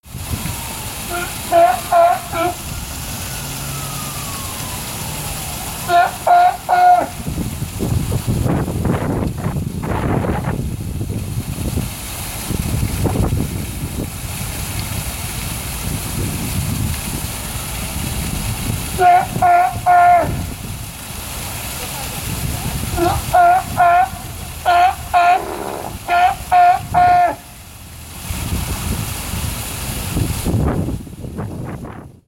The Roar of the Sea Lion in the City
We hear sirens, traffic, wind, the sound of falling water, people talking and the call of a talkative sea lion.
Queens Zoo, NYC
Posted in Field Recording | Tagged city , Queens , sea lion , wind , Zoo | Leave a comment |
QueensZoo.mp3